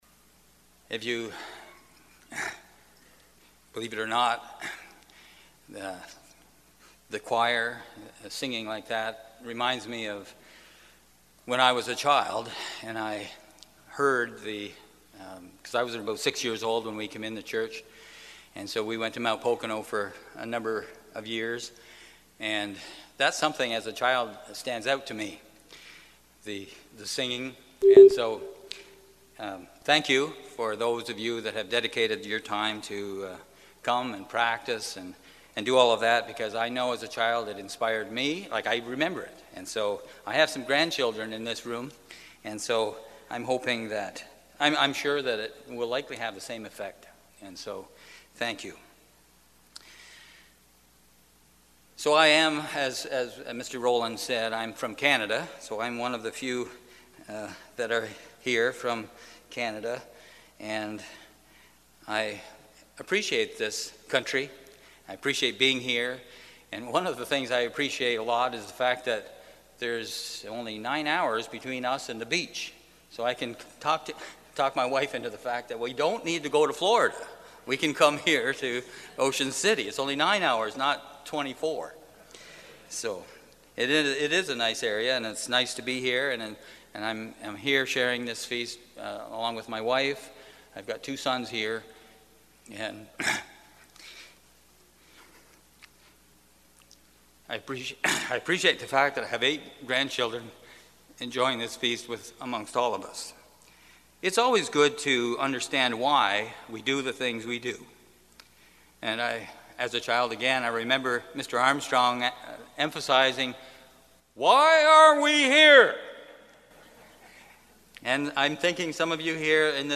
This sermon was given at the Ocean City, Maryland 2023 Feast site.